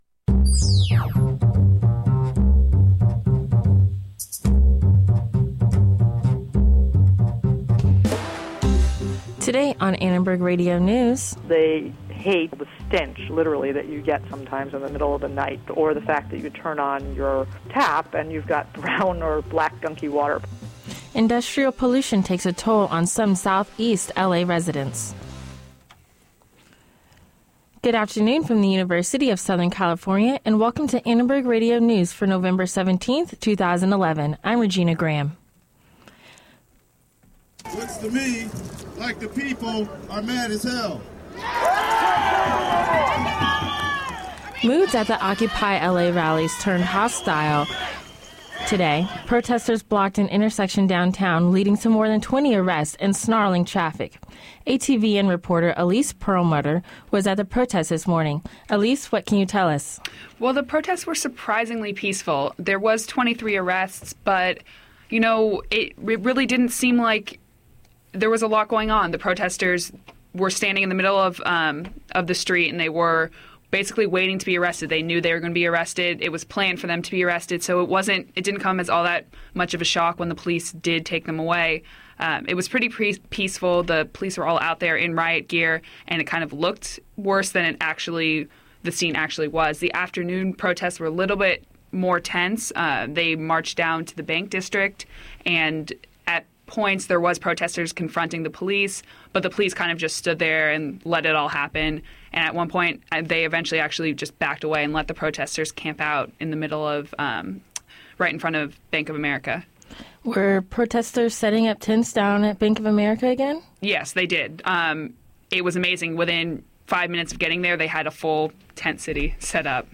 ARN Live Show - November 17, 2011 | USC Annenberg Radio News